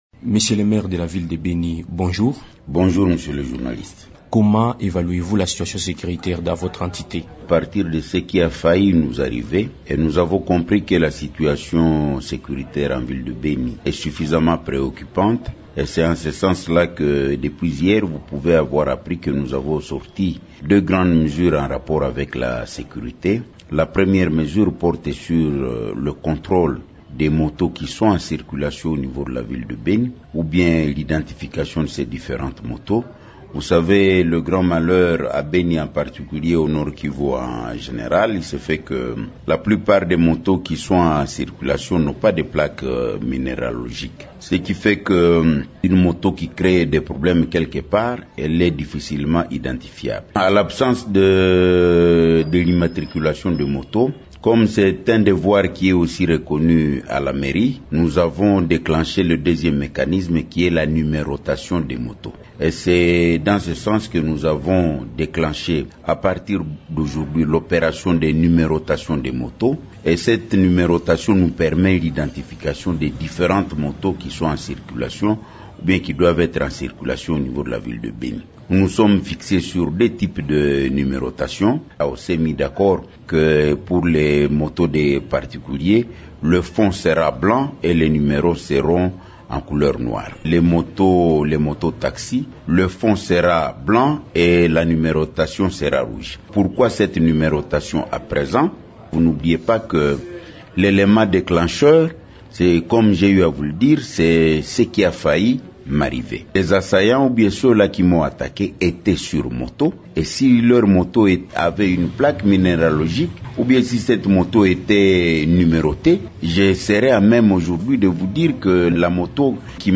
Le maire de la ville de Beni, Nyonyi Bwanakawa, s'exprime sur les mesures prises pour faire face à l'insécurité dans cette ville, six jours après avoir échappé à un attentat à la grenade artisanale.